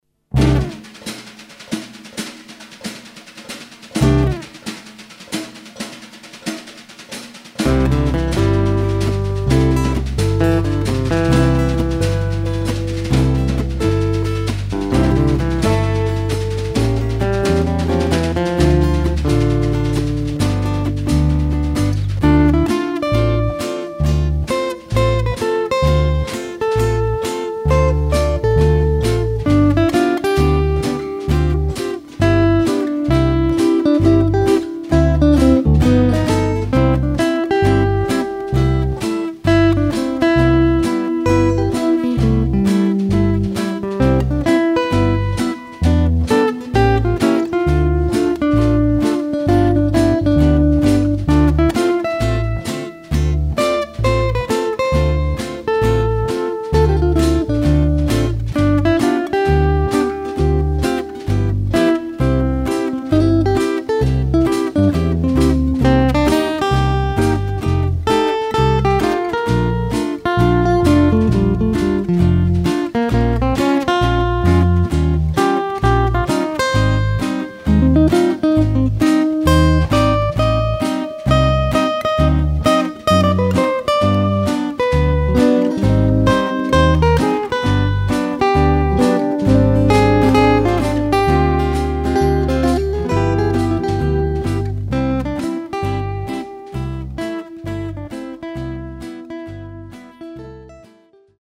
Chitarre e Basso
Pianoforte e Vibrafono
Batteria